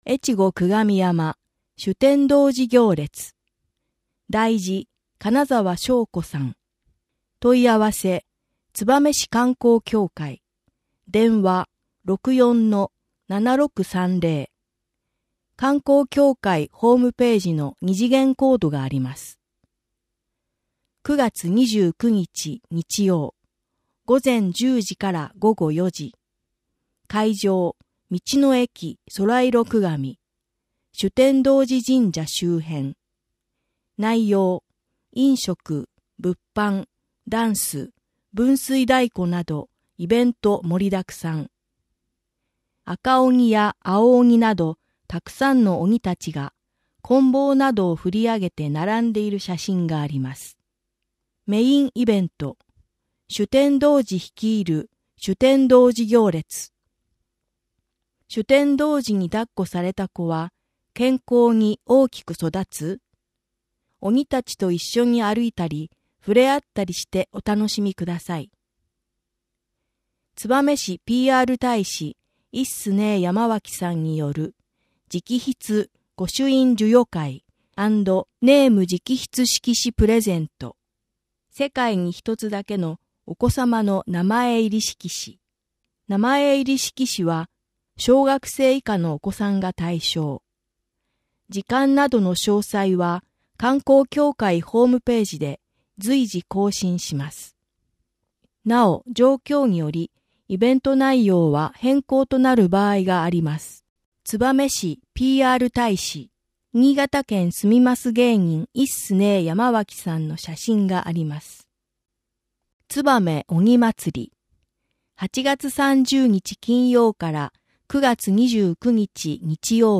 声の広報は、広報つばめを音読・録音したもので、デイジー版とMP3版があります。